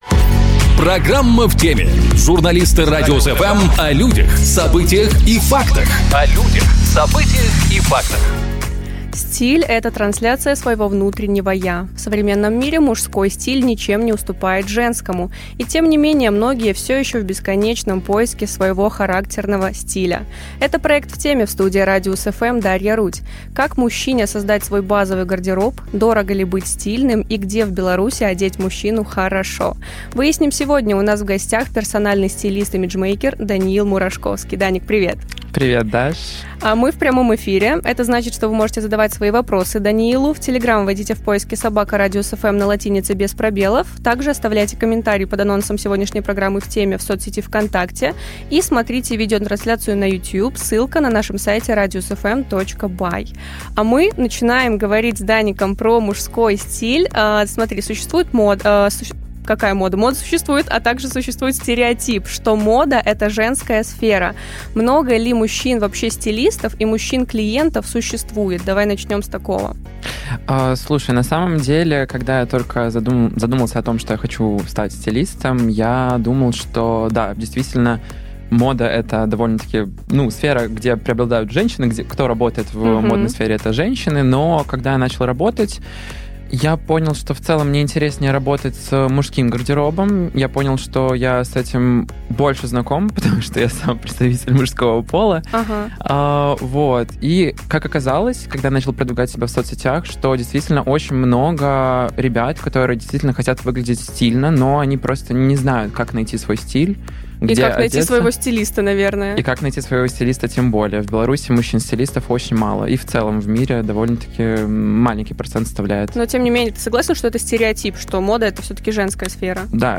У нас в гостях стилист-имиджмейкер